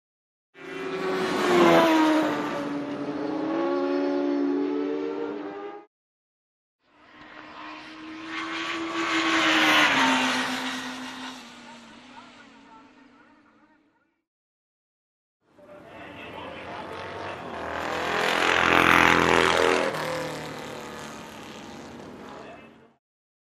Звуки мотокросса